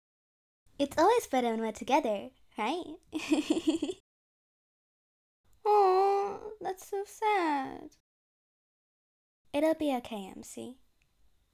Voice: Very young and childlike.